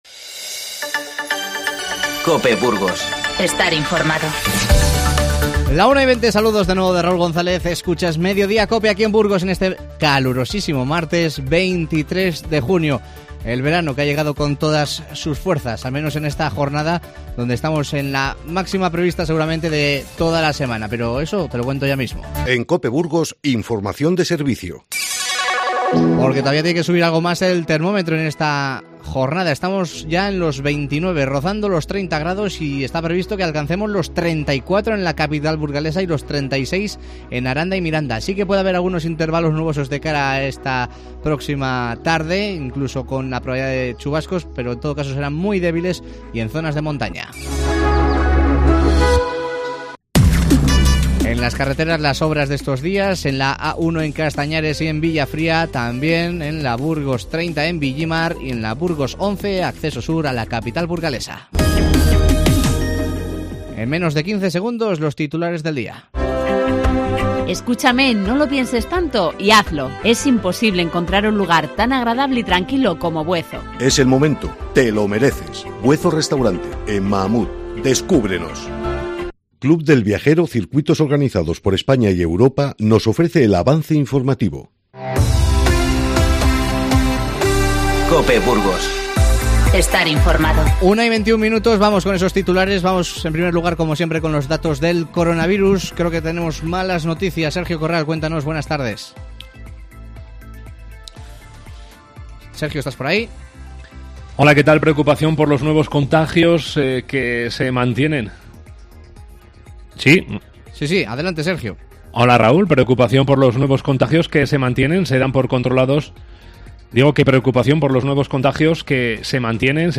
Te avanzamos las principales noticias del día y hablamos con el delegado territorial de la Junta en Burgos, Roberto Saiz, sobre la formación que se imparte estos días en las residencias para la lucha contra el covid-19.